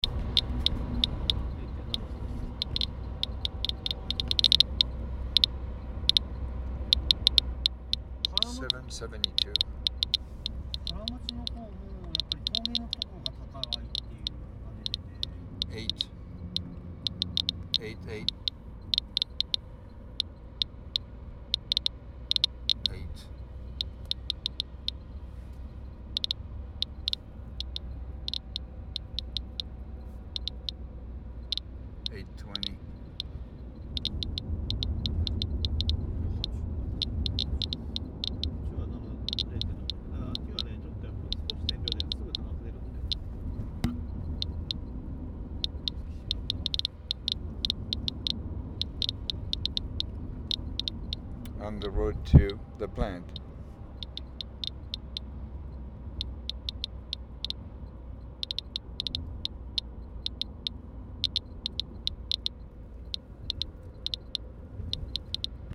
Available are recordings -Songs, Interviews of scientists, farmers, activists and misc sounds at misc public demonstrations including Chernobyl, Ukraine & Fukushima, Japan.  Here is a unedited selection.
Geiger-Fukushima.mp3